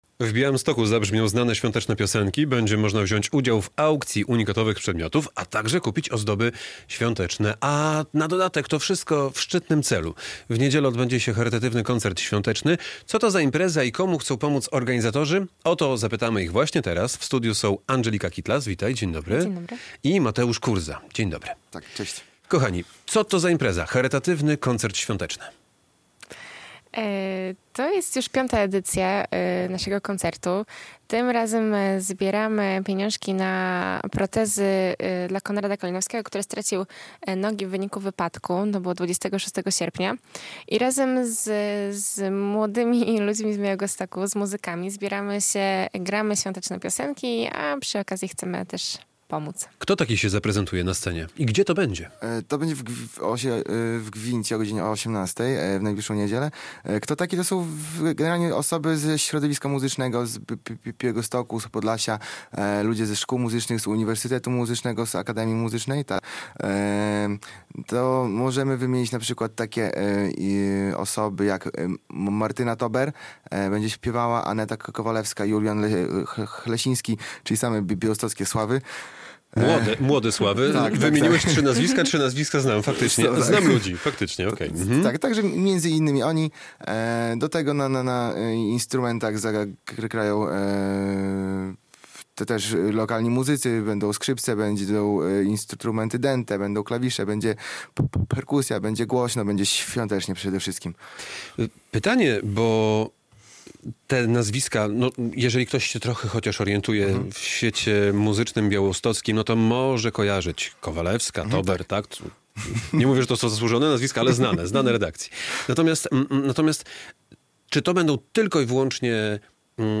organizatorzy V edycji Charytatywnego Koncertu Świątecznego